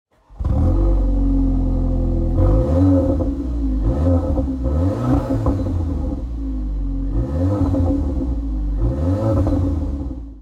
Écoutez le son du moteur !
Polo-GTI.mp3